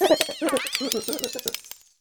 Cri de Gromago dans Pokémon Écarlate et Violet.